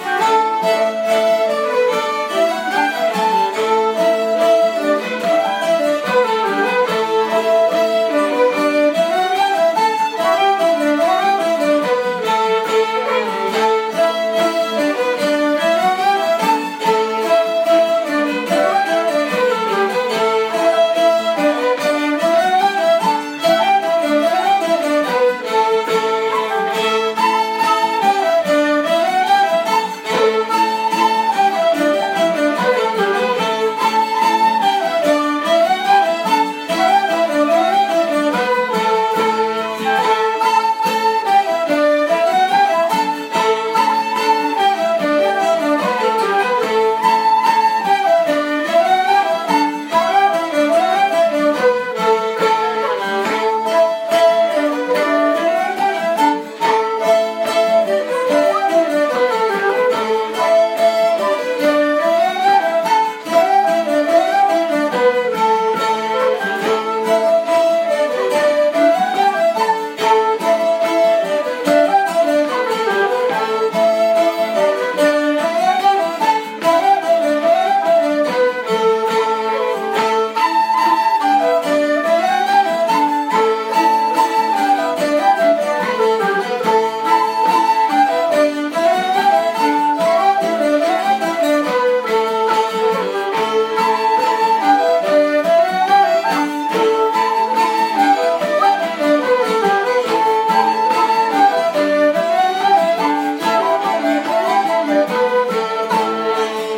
Highland_Bo-Mhin-Na-Toi-Tran.mp3